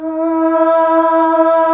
AAAAH   E.wav